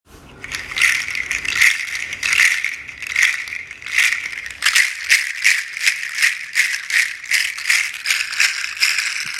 • large seed shaker originally from Togo
• loud hollow clacking sound